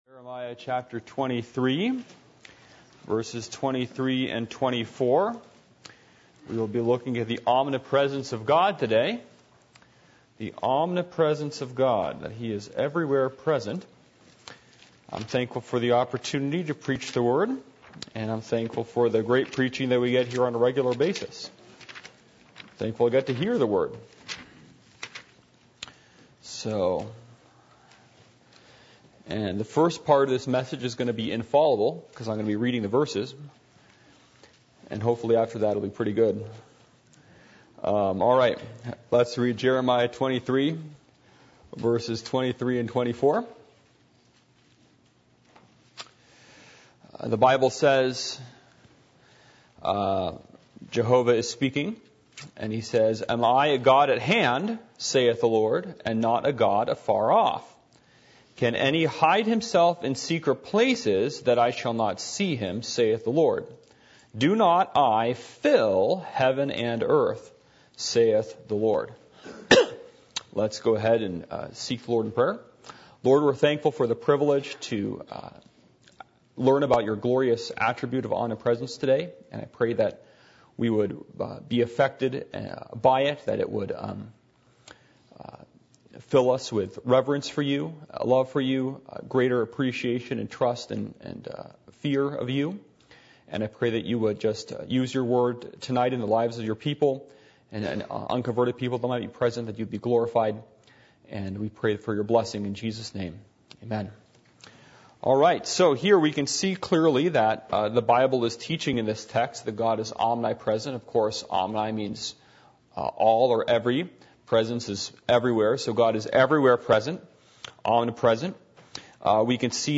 Jeremiah 23:24 Service Type: Midweek Meeting %todo_render% « The Responsibility of a Pastor to God and to his Church An Overcomer or a Succumer?